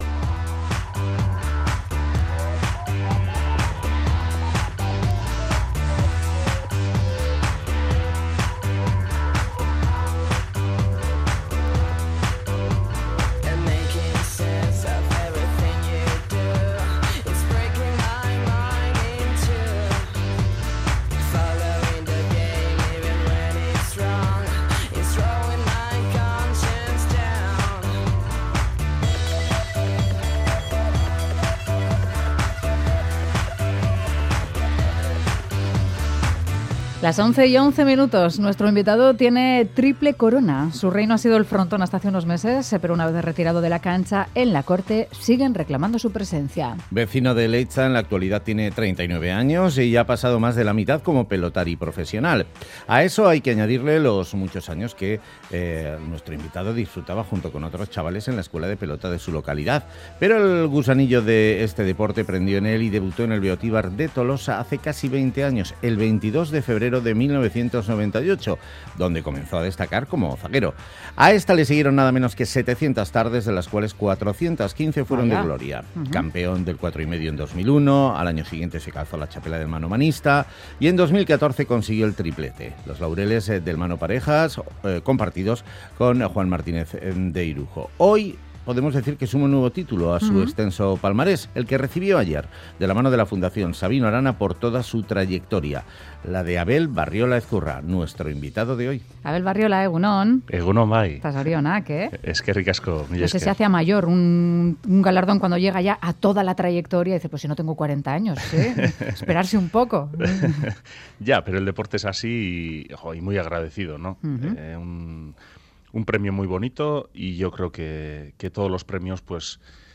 Audio: Charlamos con el ex-pelotari de Leitza Abel Barriola horas después de que recibiese el Premio Sabino Arana 2018 por su trayectoria y valores deportivos.